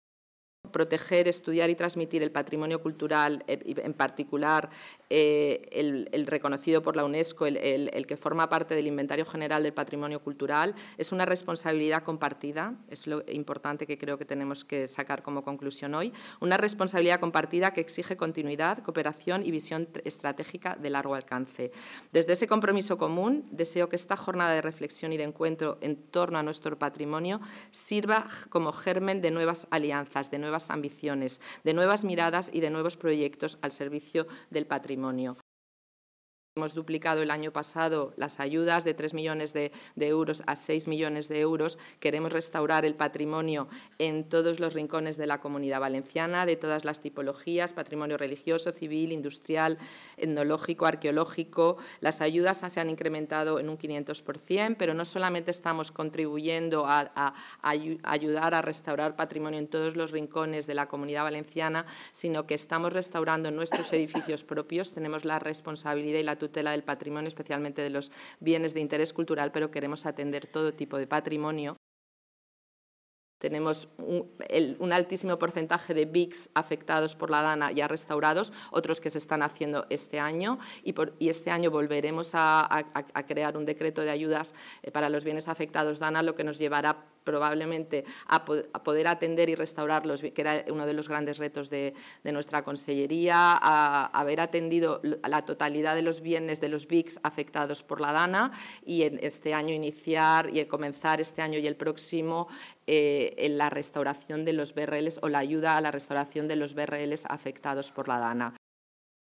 Así lo ha señalado la secretaria autonómica de Cultura, Marta Alonso, durante la inauguración de una jornada celebrada en la Lonja de la Seda de Valencia con motivo del Día Internacional de los Monumentos y Sitios.